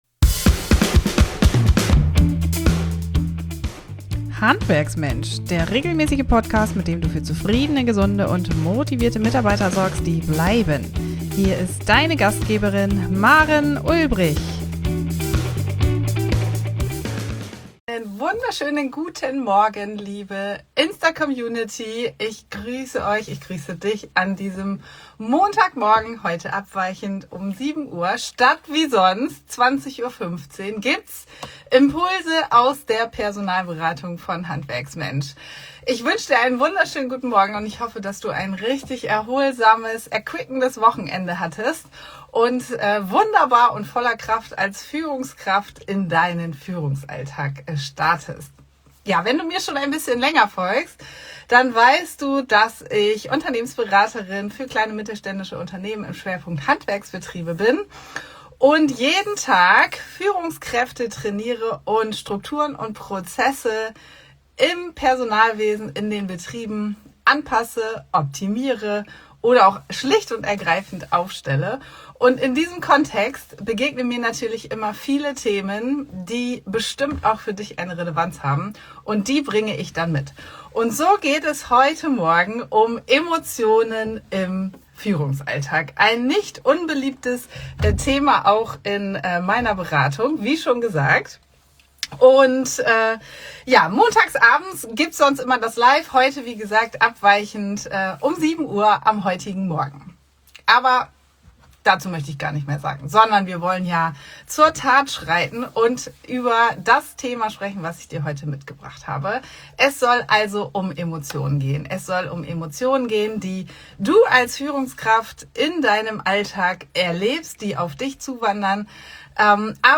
Den Live-Mitschnitt wandeln wir für Dich in eine Podcastfolge sowie ein Video um, damit Du Dir jederzeit und überall, die spannenden Inhalte anhören/ansehen kannst.